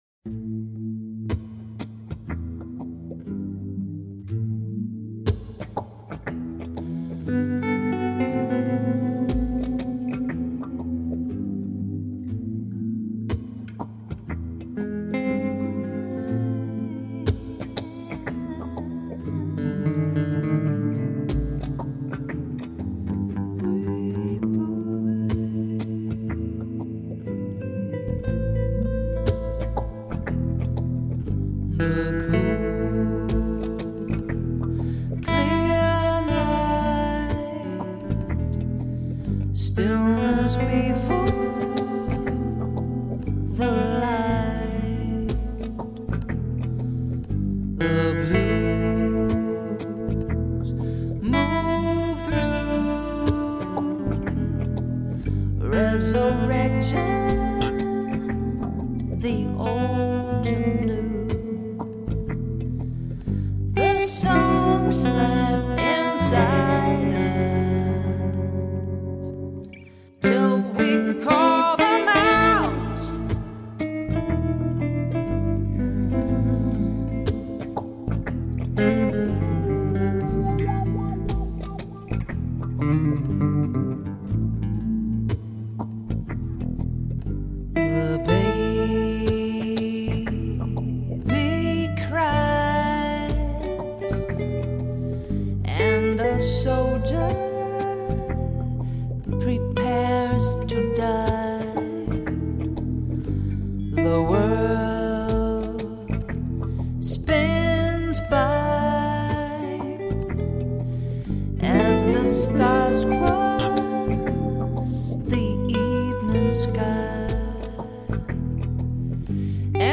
Genre: Contemporary Pop.